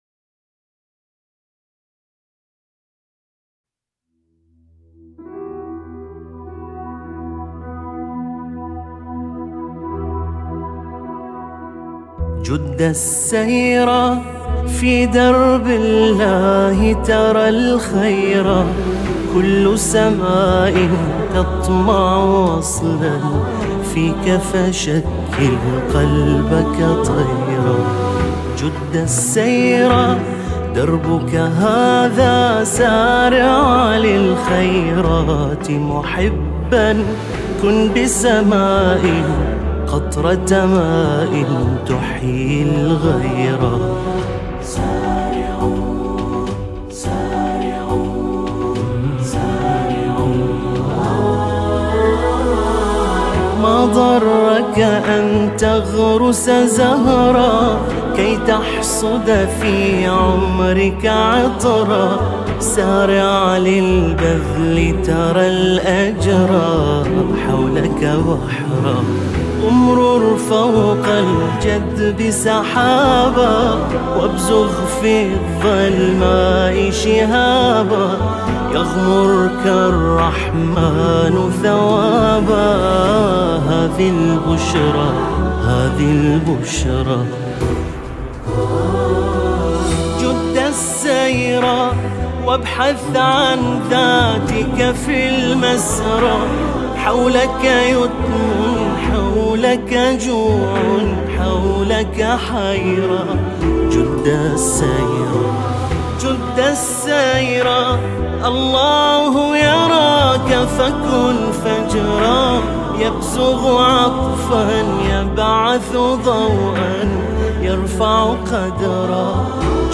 نشيد
الهندسه الصوتية والتوزيع: